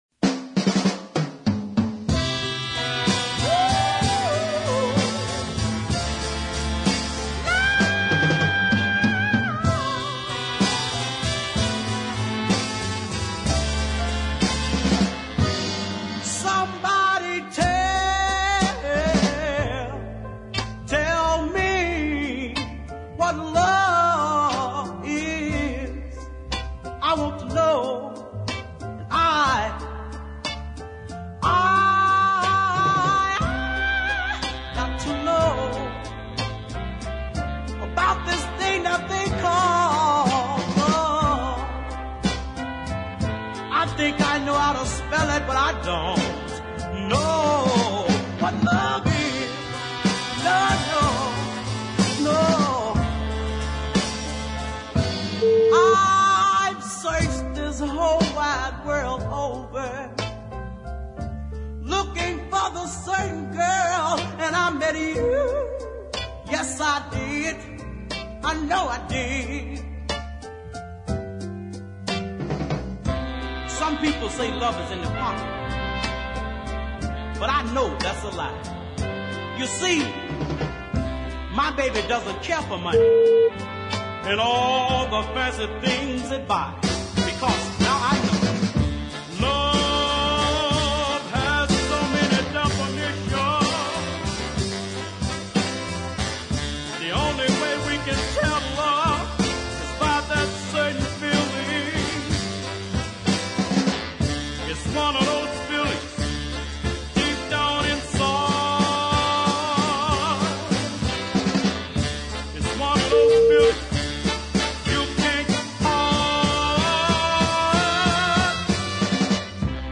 chugging ballad soul
high voltage singing
a well crafted arrangement